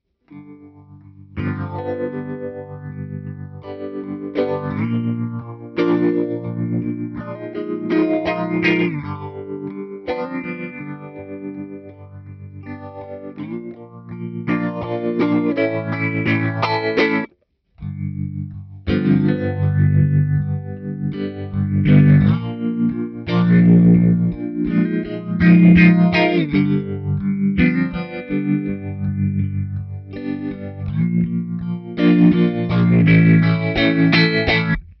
We recorded all the sample with a Fender Telecaster. No EQ, no compression, no reverb, just the guitar plugged into the audio interface. In the first part of the audio sample you hear the Deluxe1 and in the second part you hear the TwinR.
Clean Tone
P90 (phaser) + Tremolo
Pha90-phaser-Tremolo.mp3